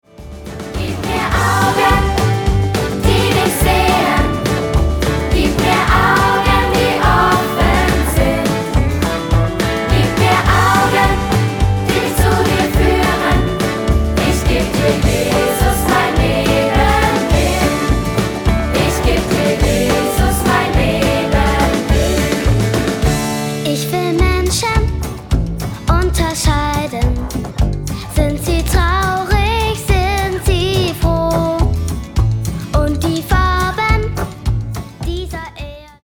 Musical-CD